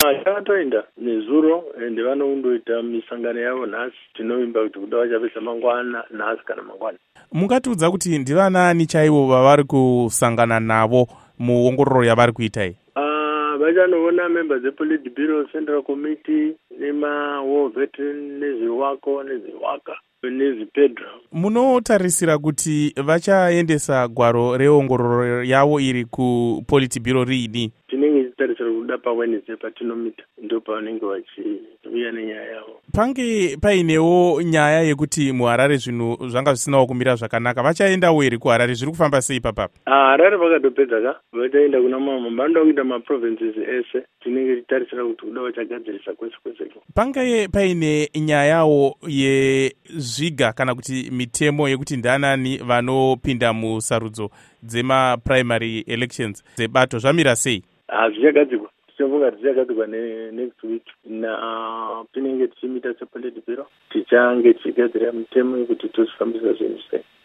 Hurukuro naVaRugare Gumbo